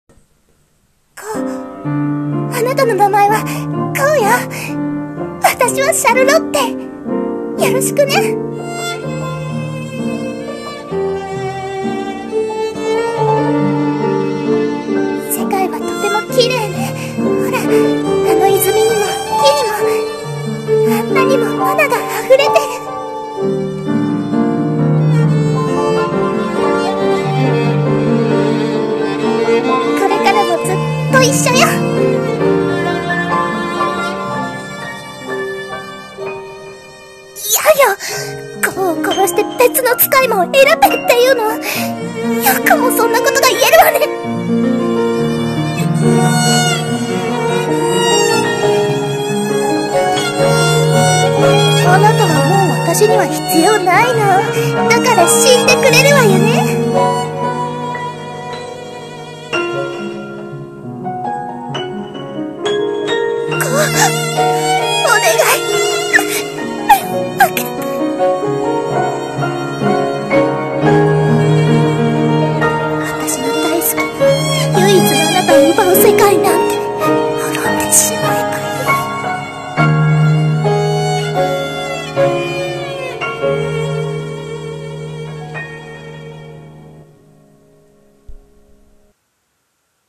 【2人声劇】僕の魔女様